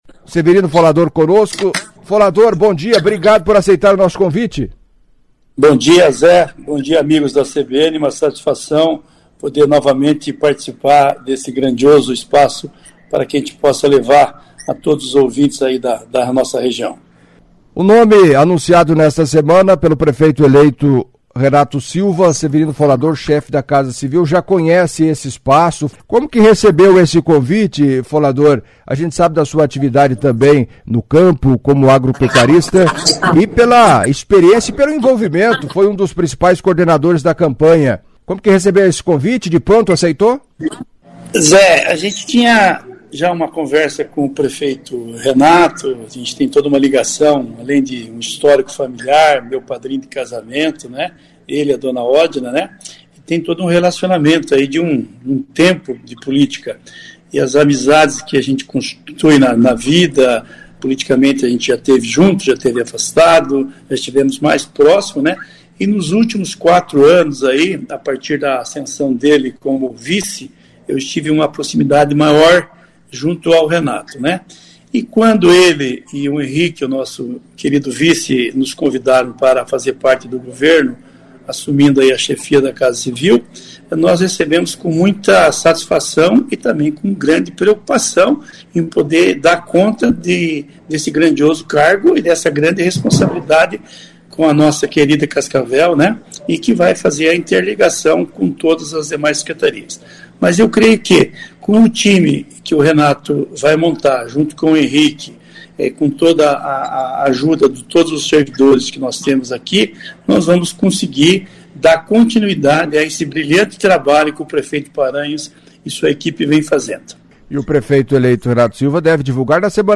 Em entrevista à CBN nesta sexta-feira (06) Severino Folador, anunciado na quarta-feira (04) pelo prefeito eleito Renato Silva como chefe da Casa Civil da Prefeitura de Cascavel a partir de janeiro, detalhou como será o trabalho e ao responder perguntas de ouvintes comentou sobre temas como: transporte público; equipe de governo; possível participação de Paranhos na Administração; ambiente na transição de governo e eleição da Mesa Diretora da Câmara.